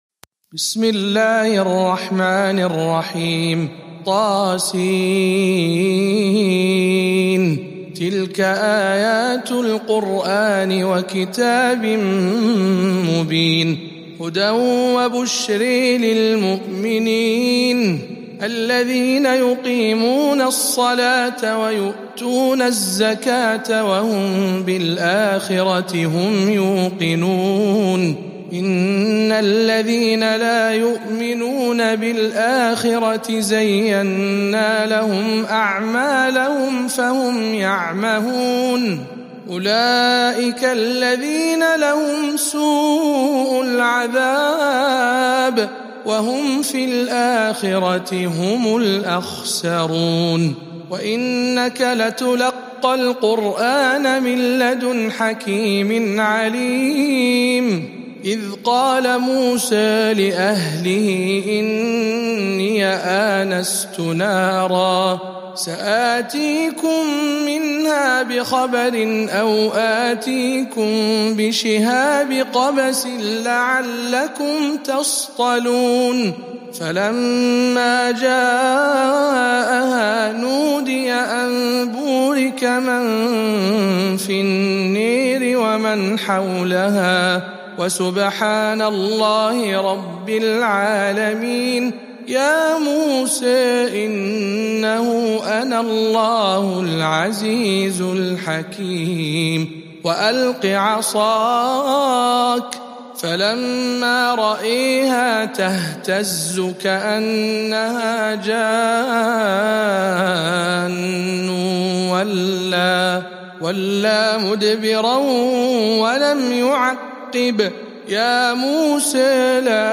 18. سورة النمل برواية الدوري عن أبي عمرو